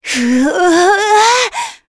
Shamilla-Vox_Casting3.wav